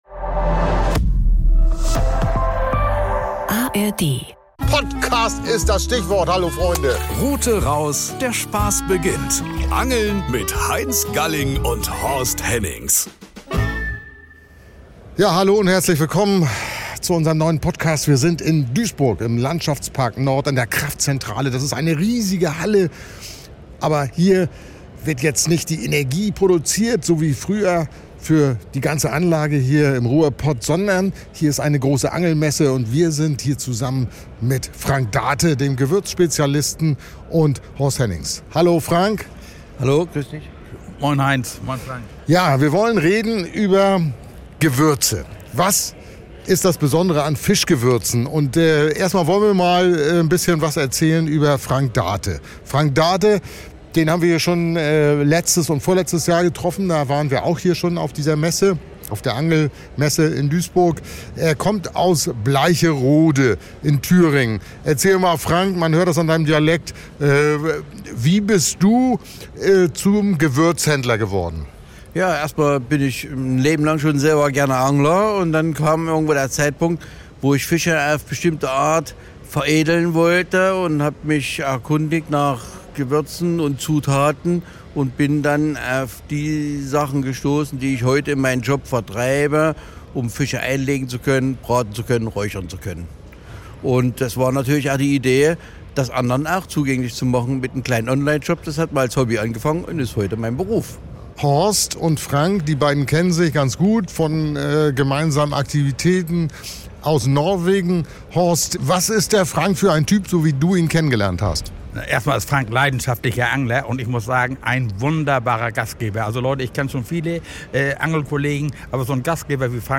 Auf der Angelmesse in Duisburg